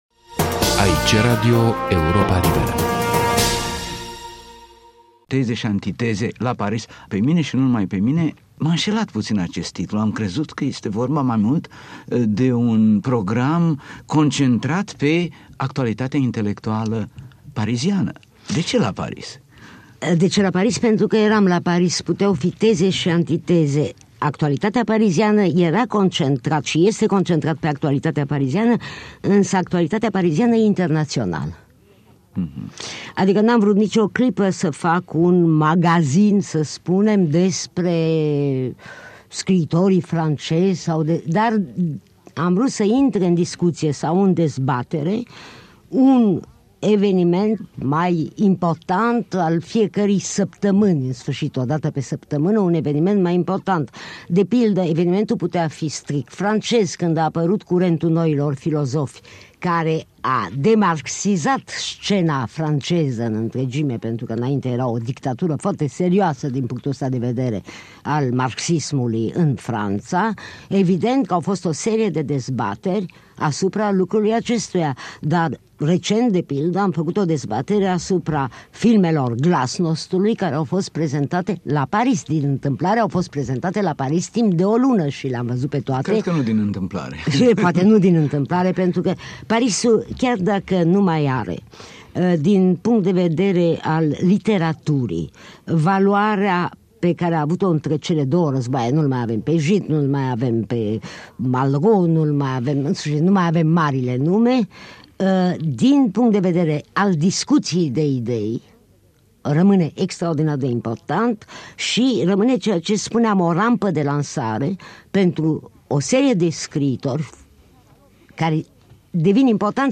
în dialog cu Monica Lovinescu